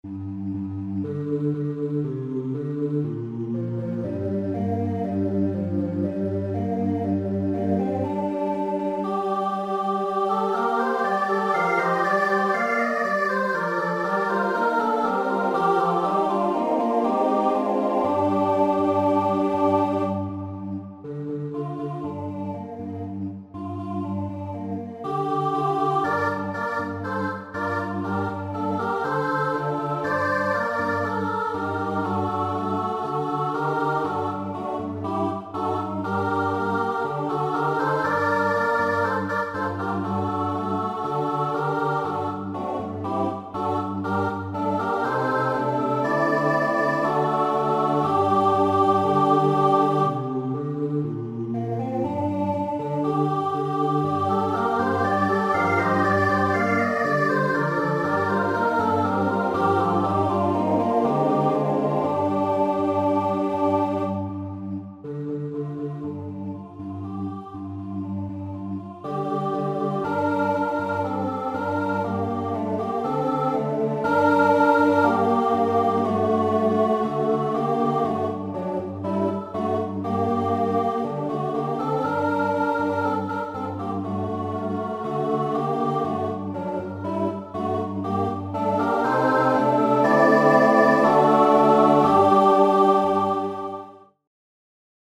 SSATB choir a cappella